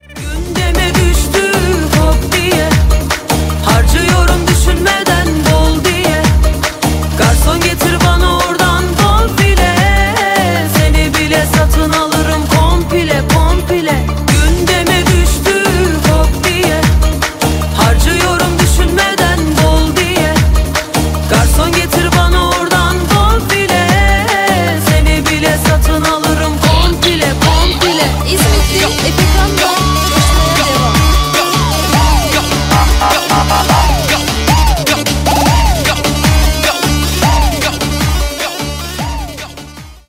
Rap/Hip-Hop